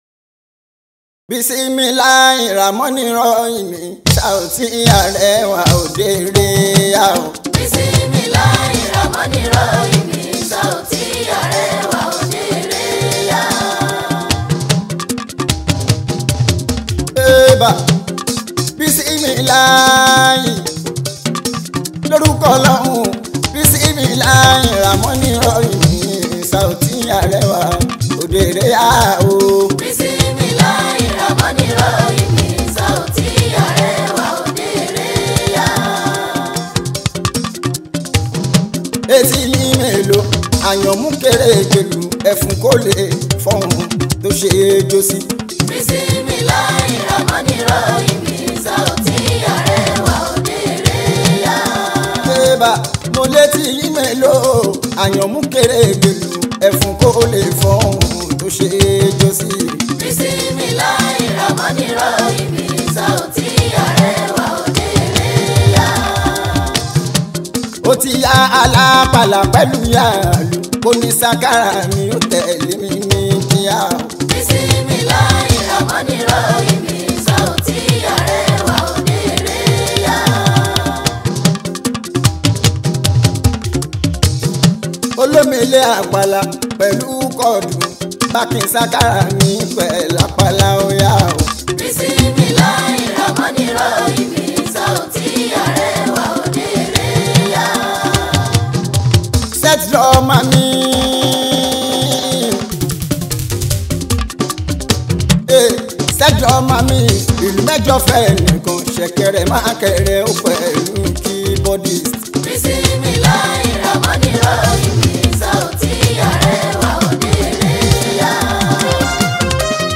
Nigerian Yoruba Fuji track
Fuji Music